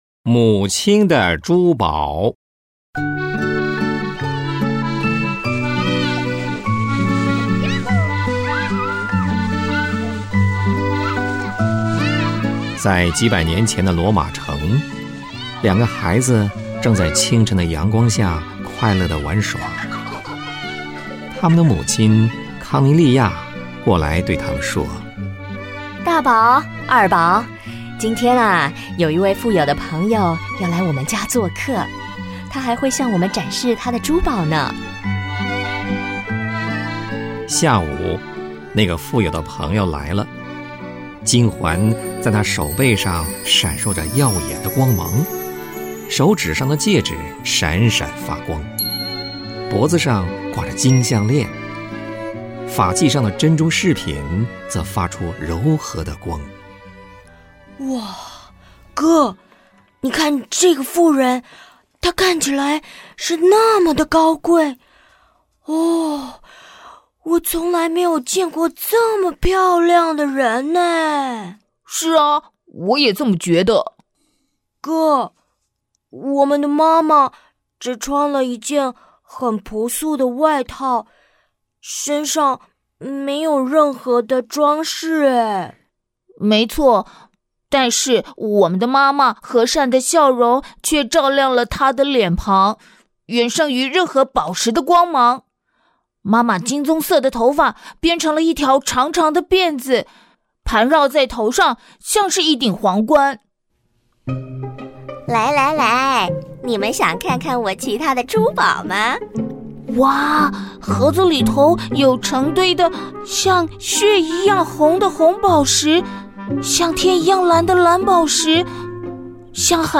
親愛的朋友，這世界充滿著許多關於母愛的故事，在這推廣節目裡，遠東製播了許多關於母愛的微廣播劇；